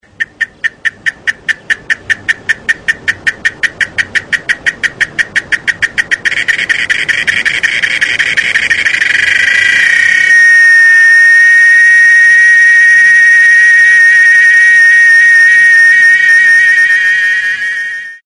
chicharra cuco